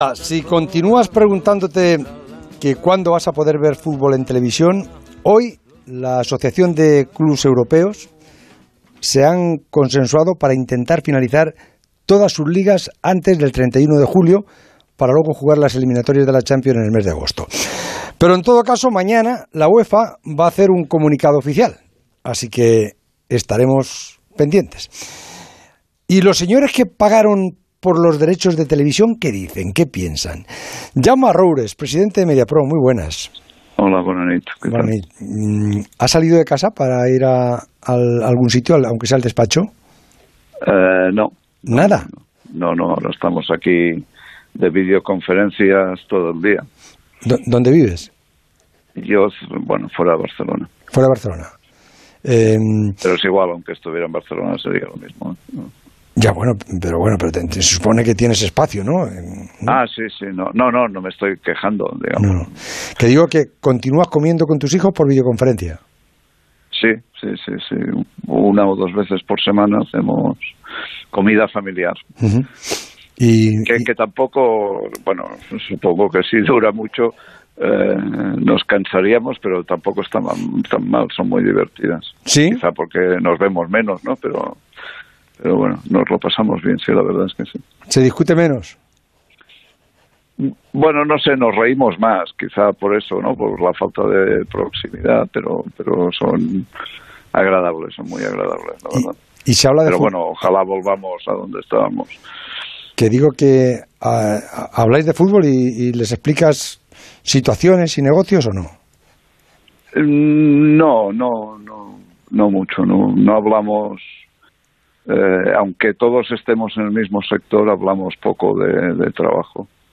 El presidente de Mediapro, Jaume Roures, pasó en la noche del miércoles por los micrófonos de El Transistor de Onda Cero